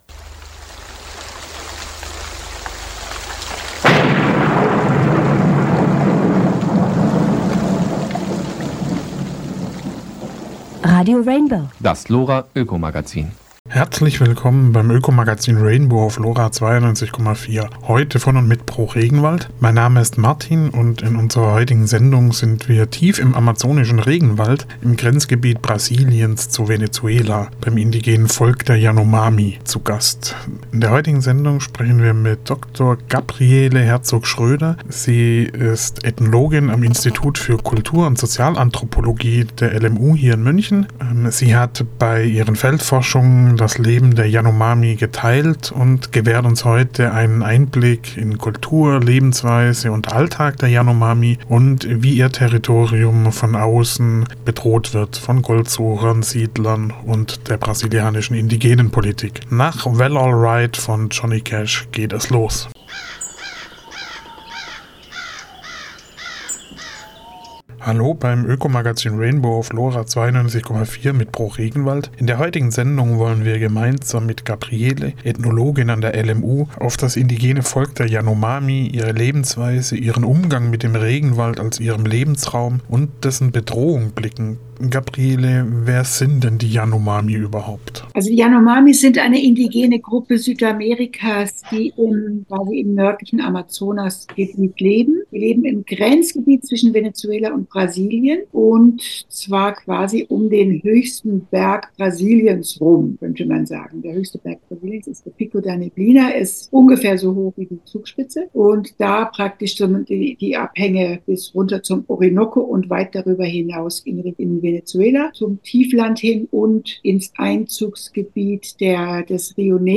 Radio und Gespräche von/mit Pro REGENWALD
Hinweis: die hier gespeicherte Sendung ist ohne die ursprünglich mit ausgestrahlten Musikstücke, da wir keine Urheberrechte verletzen wollen.